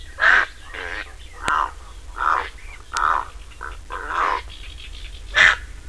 Purpurreiher (Ardea purpurea)
Stimme: ähnlich Graureiher, aber weniger ruffreudig, im Flug »kreck«.
Ardea.purpurea.wav